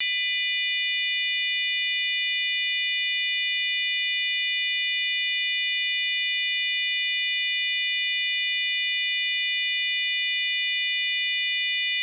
Bild 3, multiton med symmetrisk olinjäritet motsvarande tredjeton på -30dB.